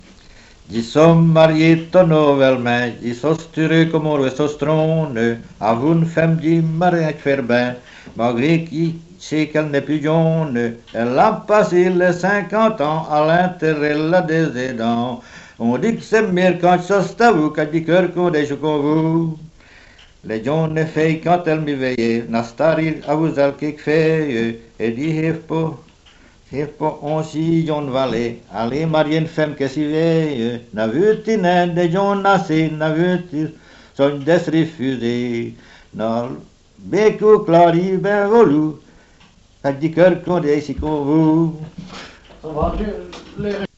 Genre : chant
Type : chanson narrative ou de divertissement
Interprète(s) : Anonyme (homme)
Lieu d'enregistrement : Dans la région de Ferrières
Support : bande magnétique